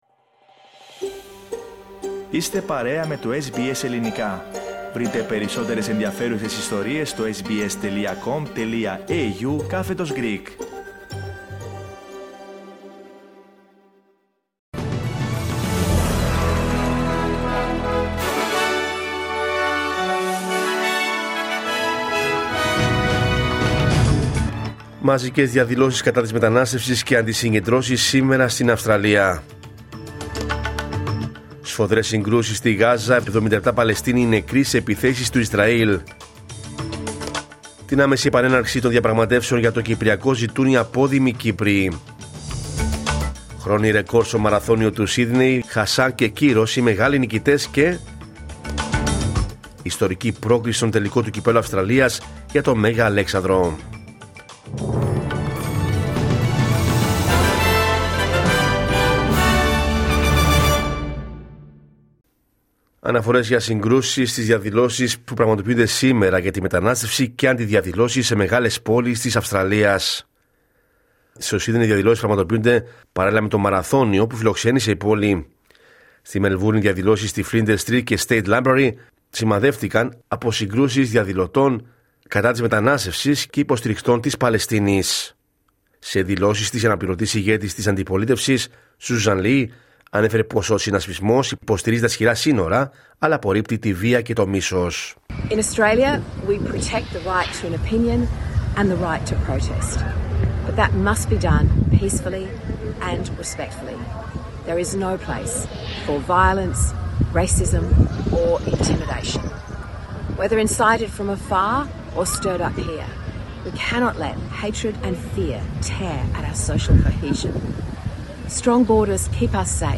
Ειδήσεις από την Αυστραλία, την Ελλάδα, την Κύπρο και τον κόσμο στο Δελτίο Ειδήσεων της Κυριακής 31 Αυγούστου 2025.